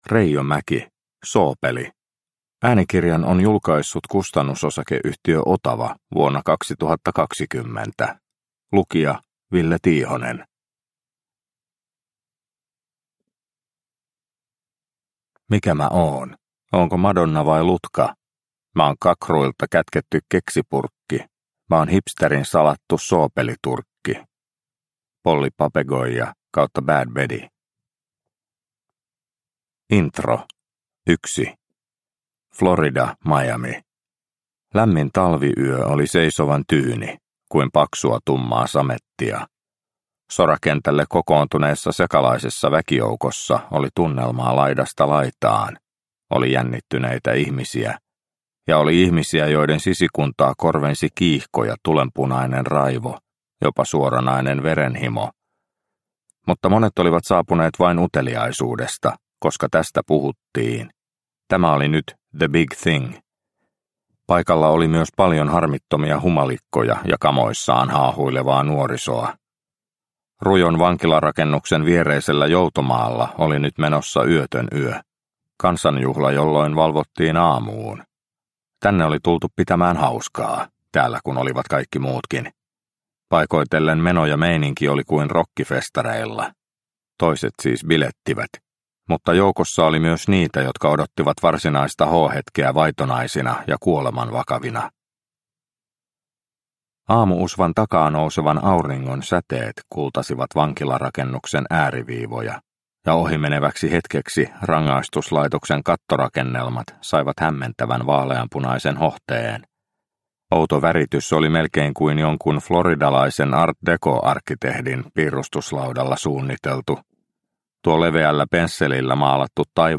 Soopeli – Ljudbok – Laddas ner